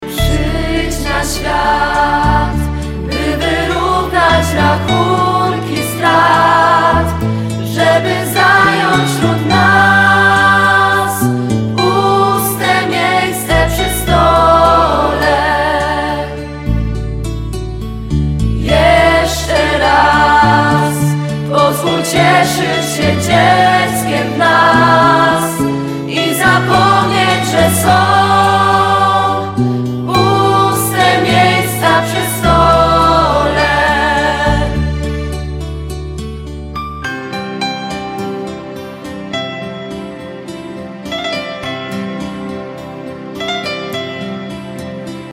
posłuchaj fragmentu kolędy w wykonaniu chóru (.mp3)